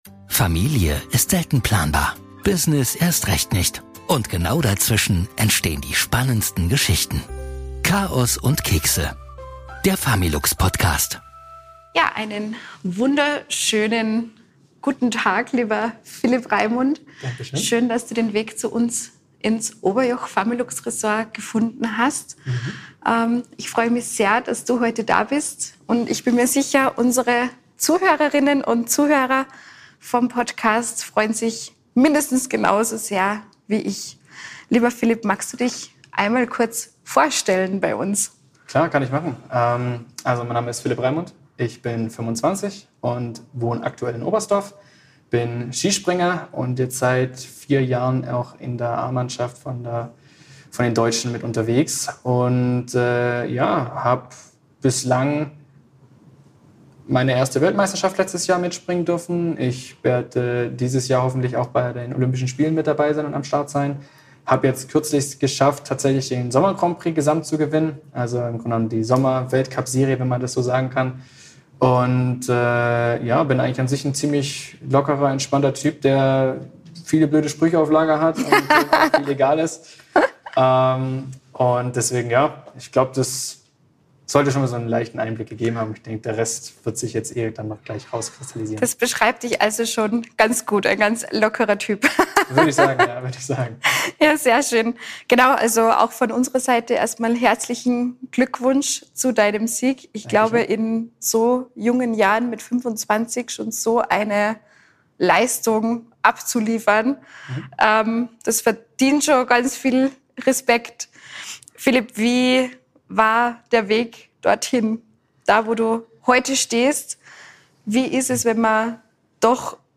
Ein offenes Gespräch über Leistung, Leidenschaft und das Leben in der Öffentlichkeit.